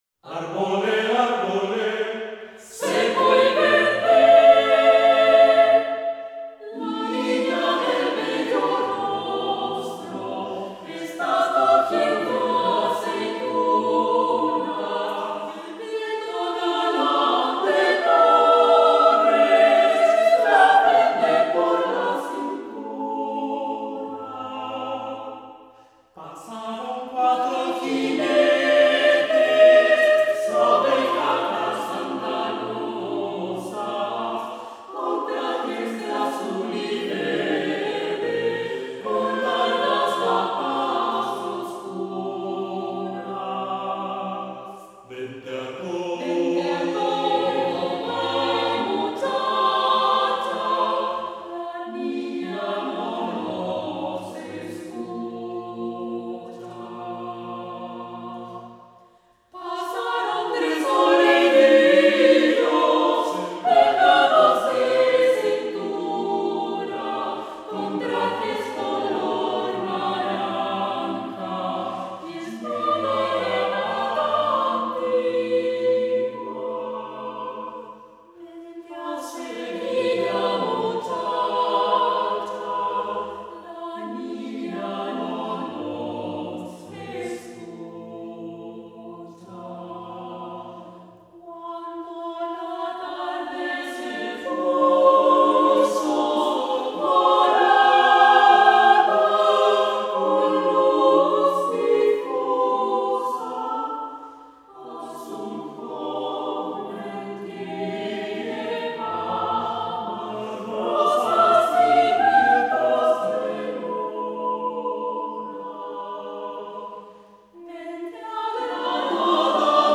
Arbolé, Arbolé para coro